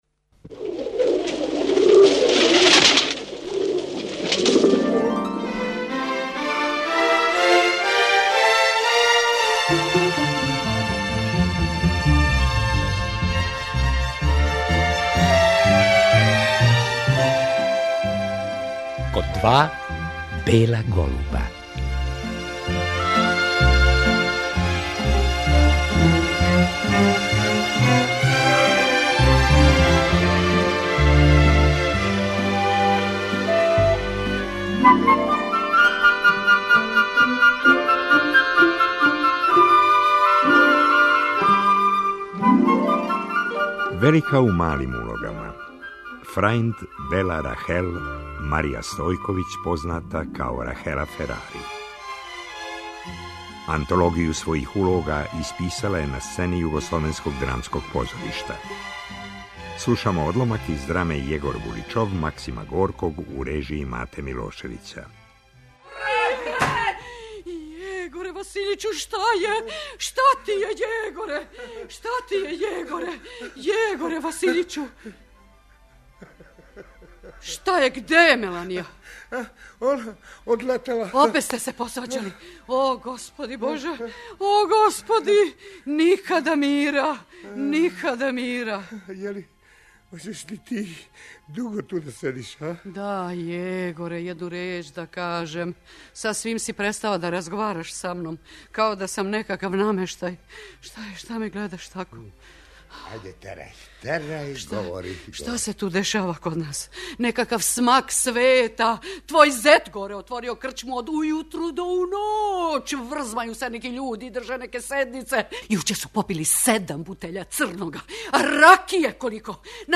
Рахела Ферари била је гост ове емисије 1987. године. Разговор је водио њен колега Петар Словенски.